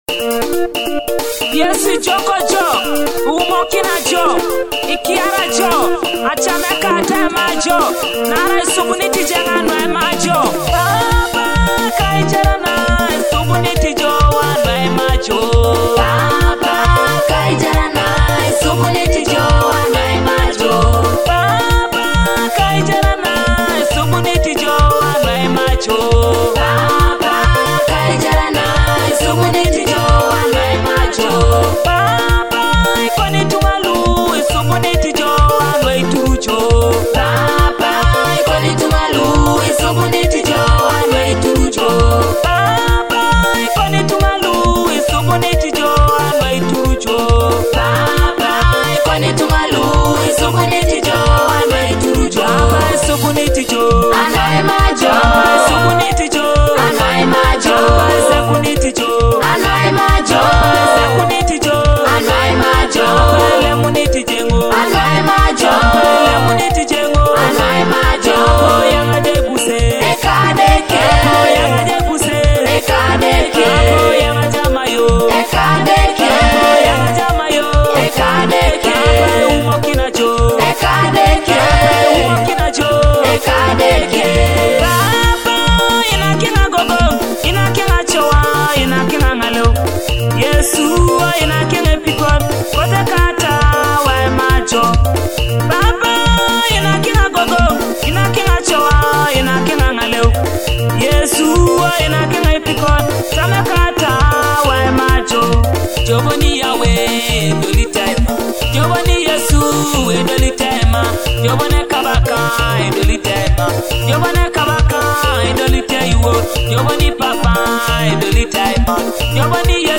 uplifting gospel praise hit